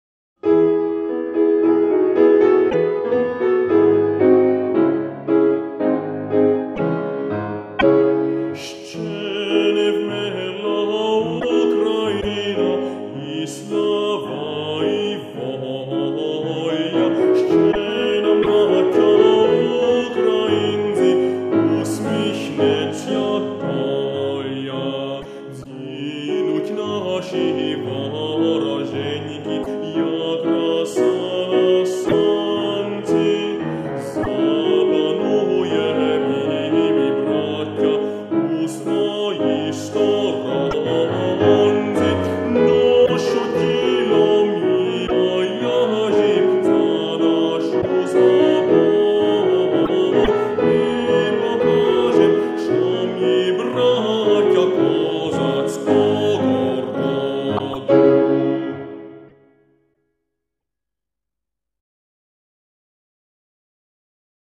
Vokalfassung